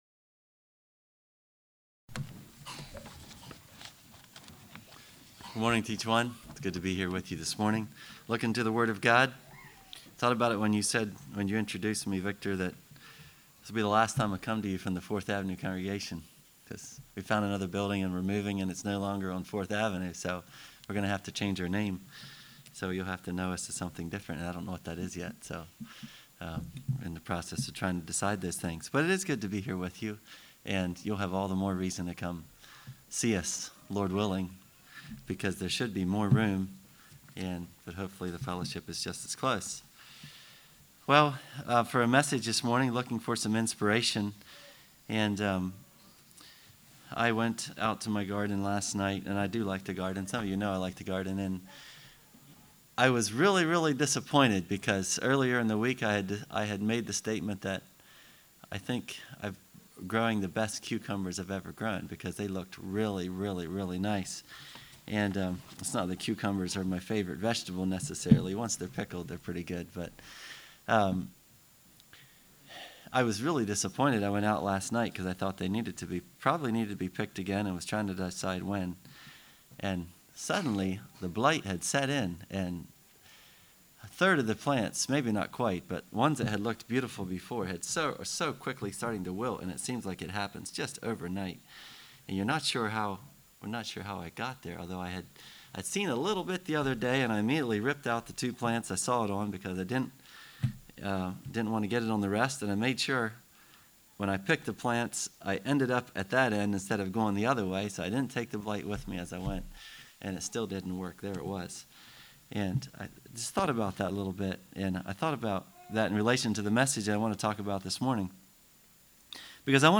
Play Now Download to Device Thriving Relationships Congregation: Winchester Speaker